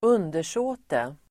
Ladda ner uttalet
Uttal: [²'un:der_så:te]